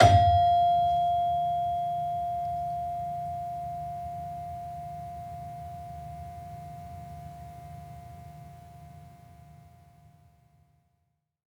Gender-3-F4-f.wav